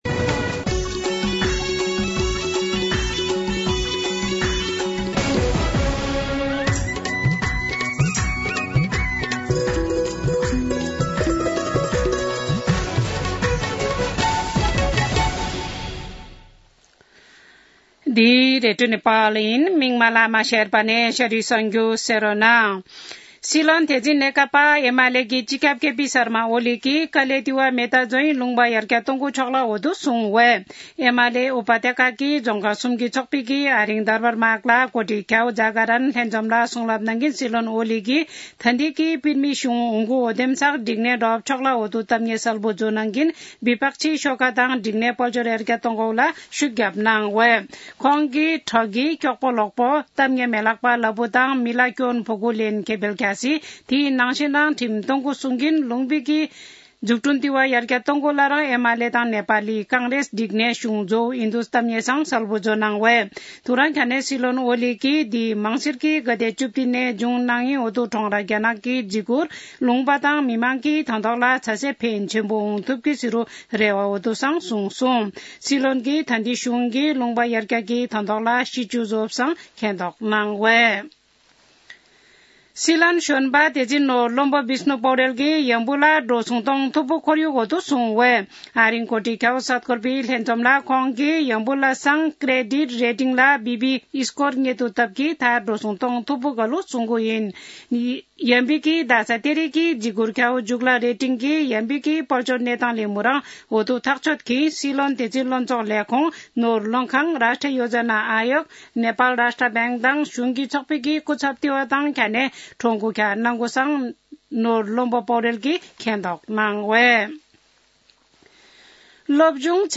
An online outlet of Nepal's national radio broadcaster
शेर्पा भाषाको समाचार : ८ मंसिर , २०८१
sherpa-news-.mp3